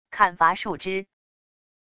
砍伐树枝wav音频